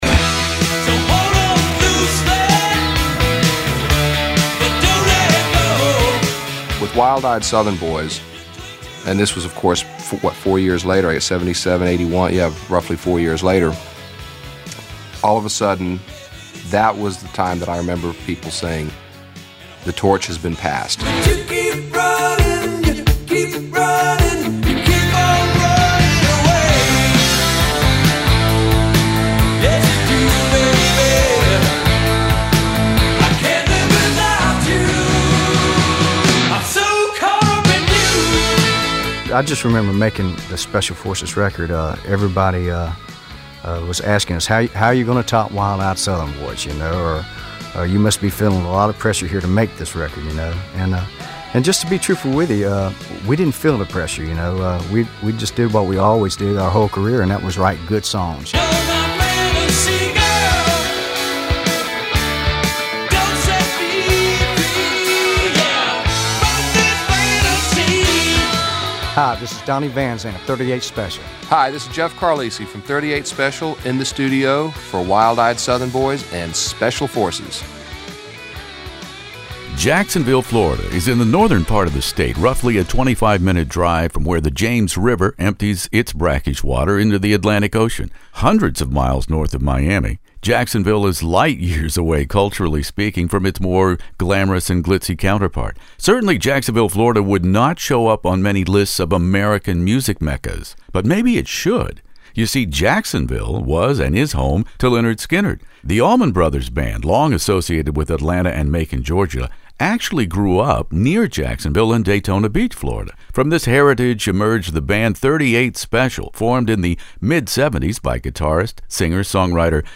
38 Special "Special Forces" interview with Donnie Van Zant, Jeff Carlisi In the Studio